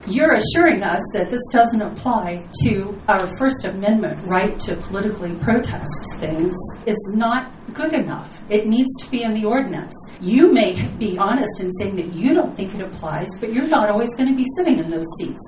Speakers at the Cumberland City Council meeting on Tuesday spoke against the permit ordinance for events in downtown.